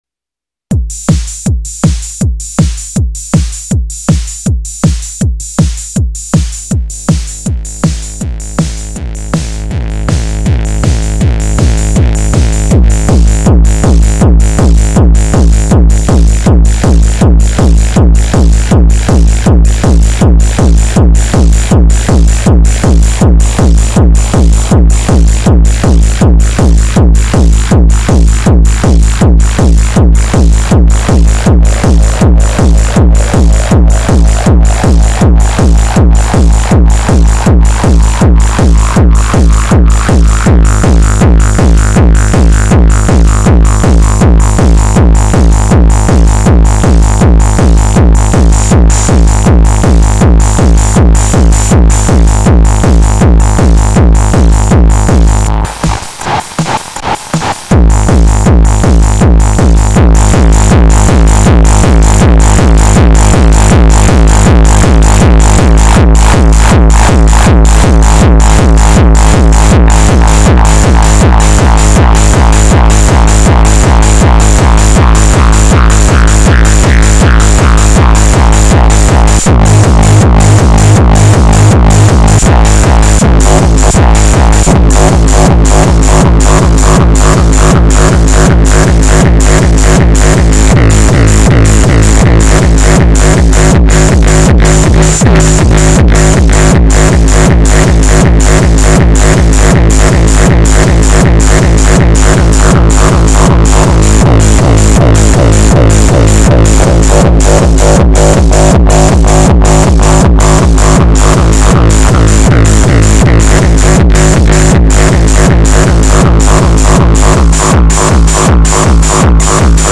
Aux send to another track, feedback.
Messing around with AD (mid freq, character) and mixer (eq, fx send, feedback), without touching AR.
Very dirty, but different tastes!